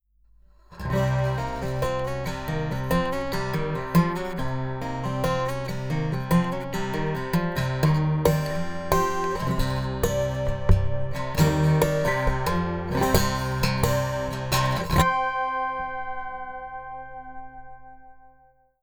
収録のForamtは48kHz/24bitです。
タッピング奏法等
ここではいわゆる"タッピング奏法"のフレーズを弾いていただきました。
いかがでしたか?全般的に共通して言えるのは「ピエゾは高域の伸びがあり、Santasticは低域にふくよかさある。」ということでしょうか?
tapPiezo.wav